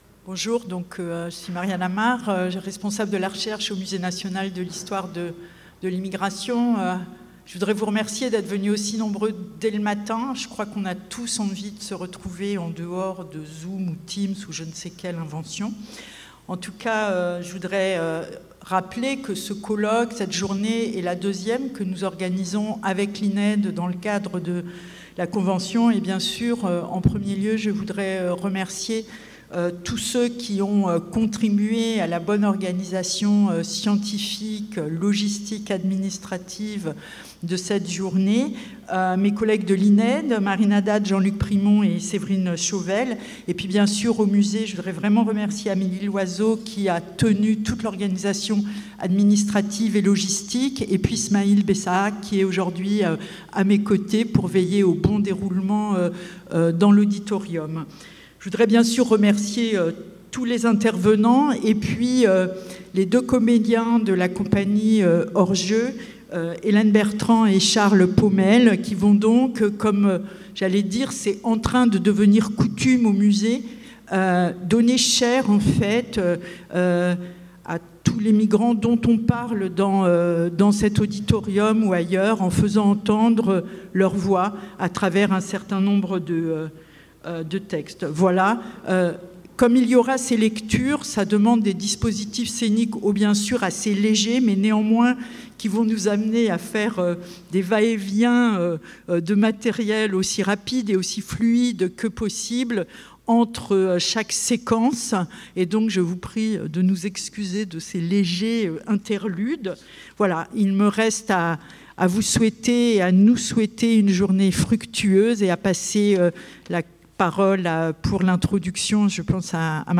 Journée d’études de l’unité Migrations Internationales et Minorités de l’Ined
Captation audio de ces présentations